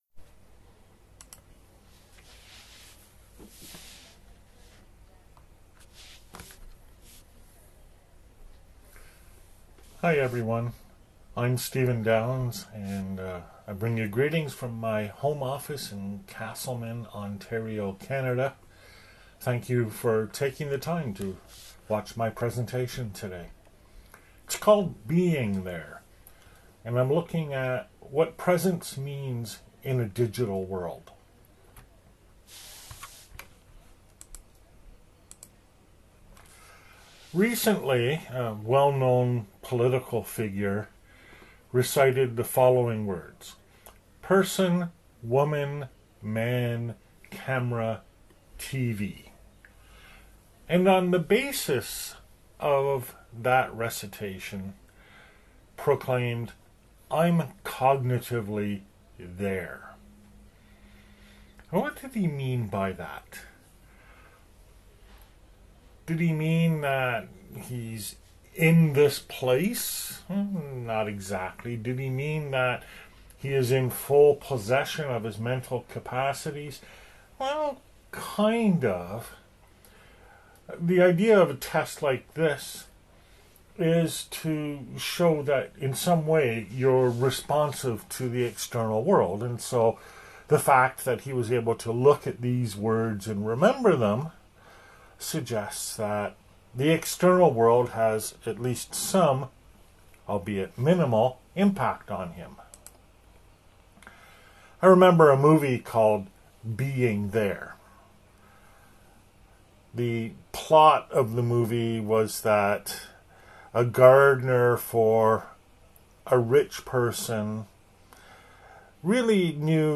via video recording, Lecture